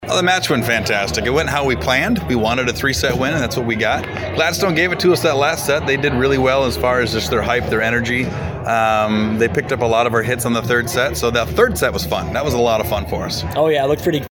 post-game comments